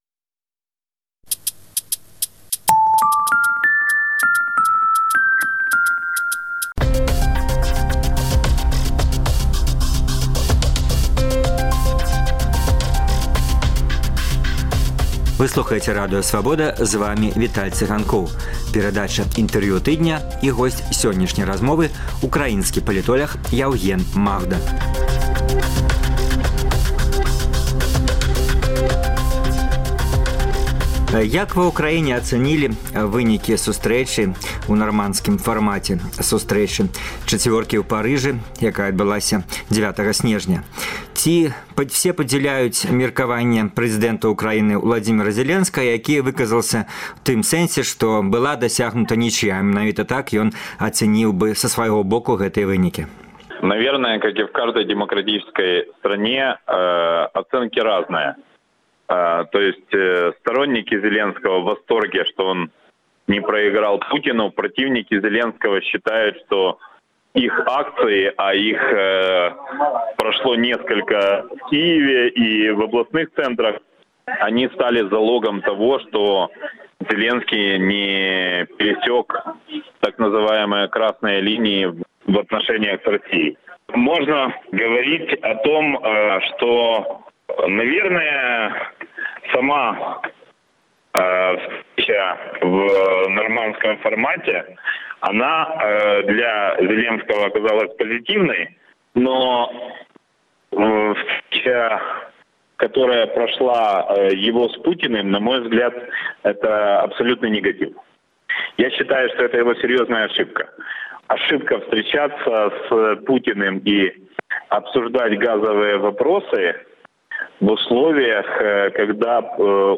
У праграме: Інтэрвію тыдня.